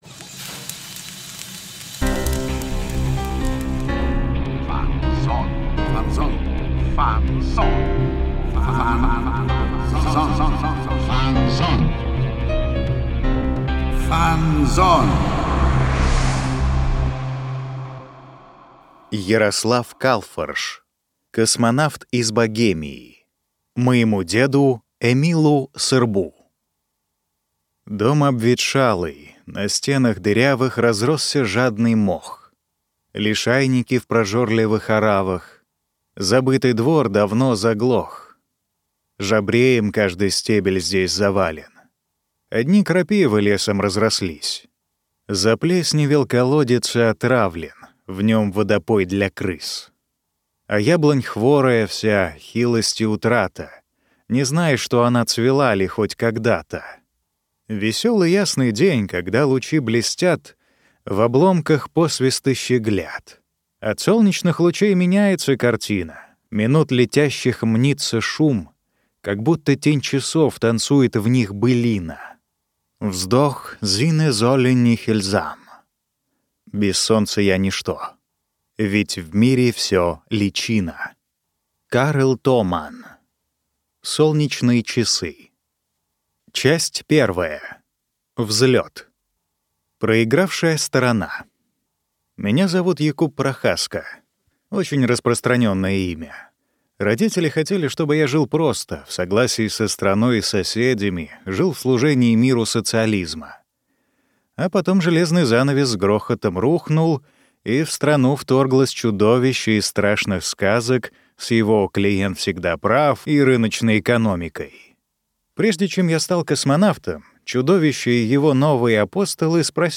Аудиокнига Космонавт из Богемии | Библиотека аудиокниг